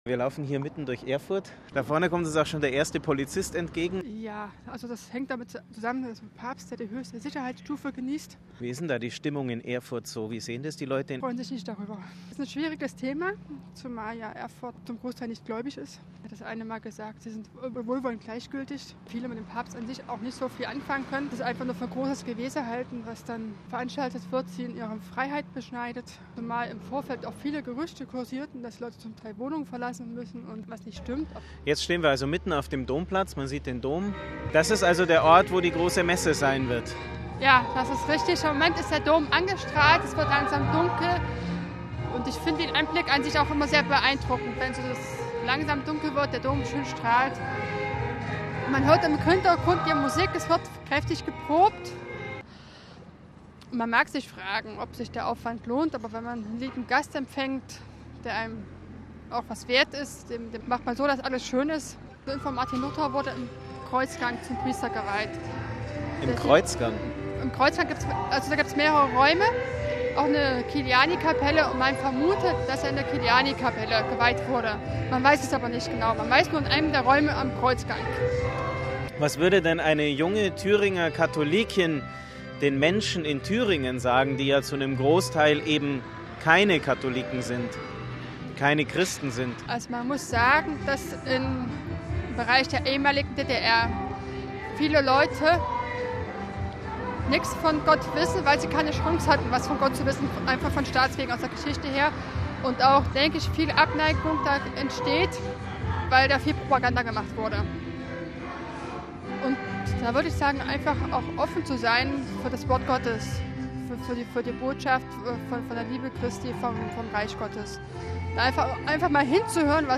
Erfurt: Ein Spaziergang